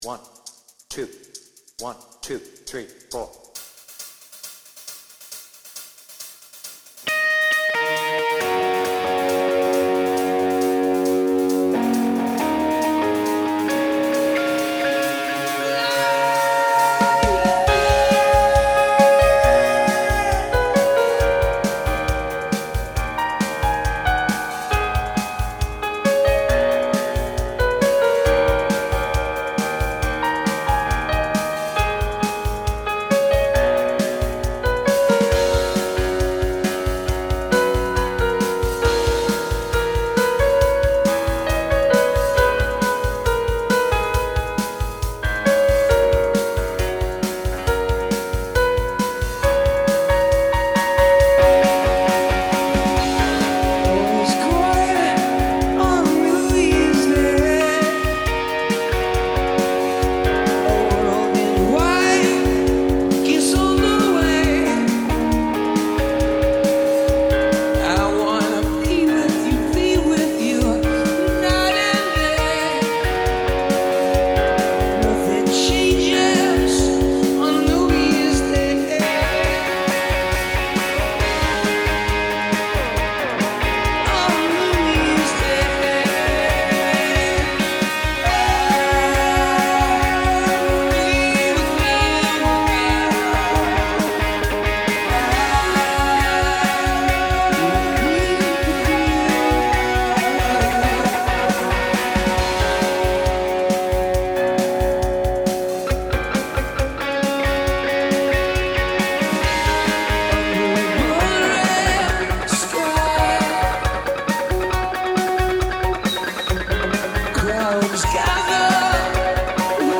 BPM : 136
Tuning : Eb
With vocals
Based on the Slane Castle version